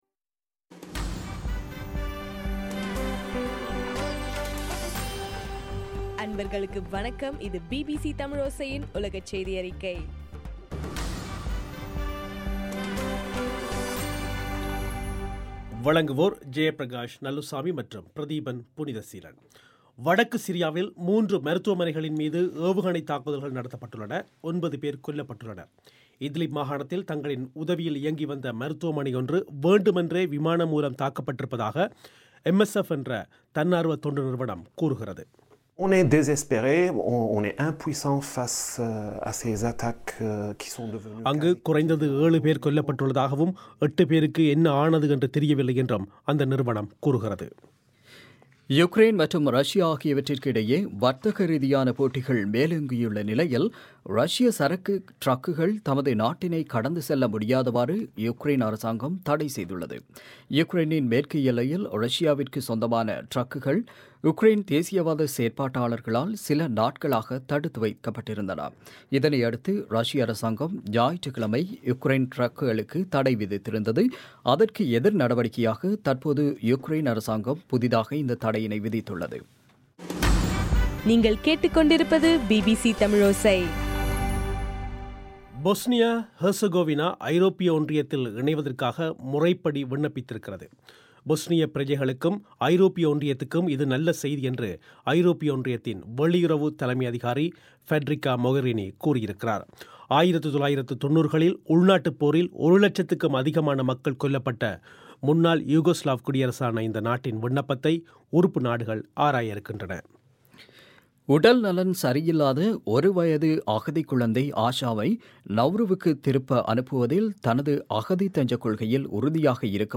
இன்றைய (பிப்ரவரி 15) பிபிசி தமிழோசை செய்தியறிக்கை